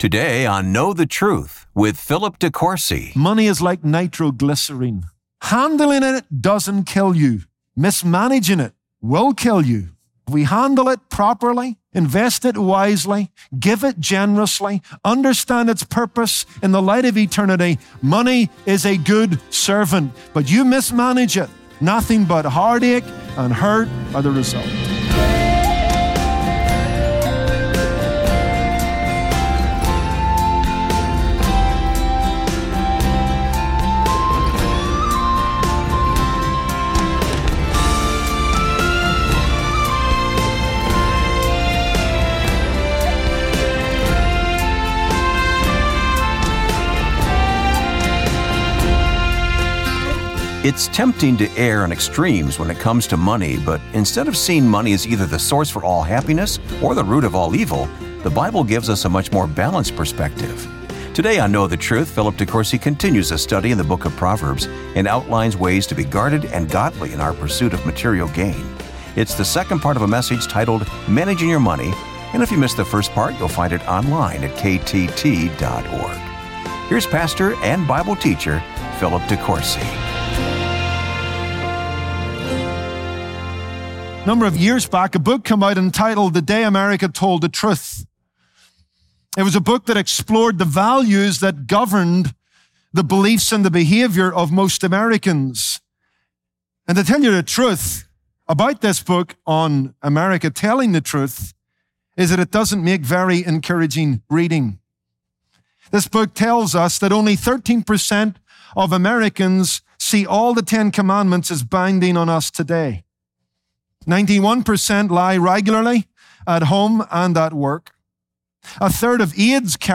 There is no denying that money is necessary for survival. But unless we determine the proper value of our resources, it is likely they will determine our values for us! On this Tuesday broadcast